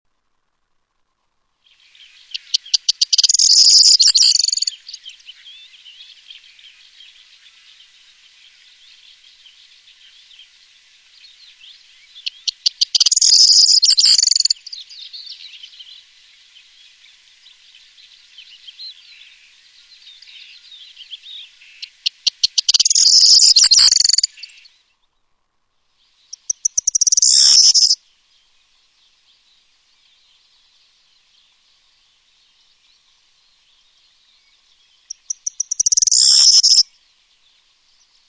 Gesang: klirrender Gesang
Gesang der Grauammer,
02 - Grauammer.mp3